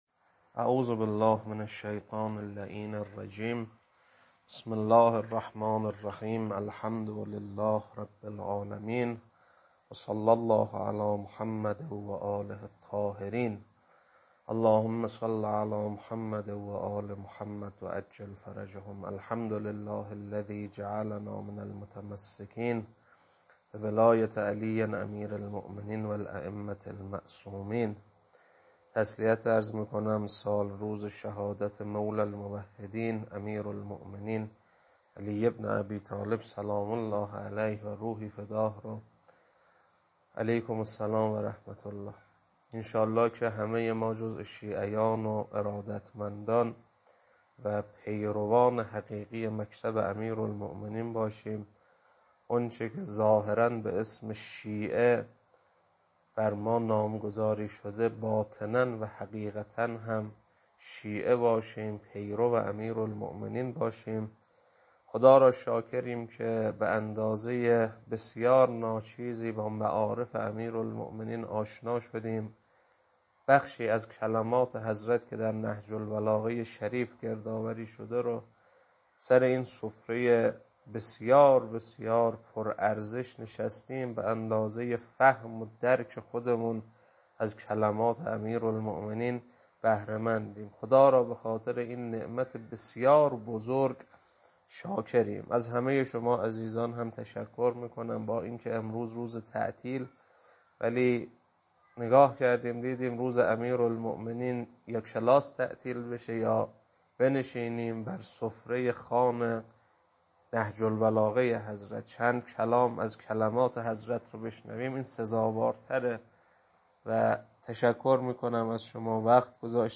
خطبه 11.mp3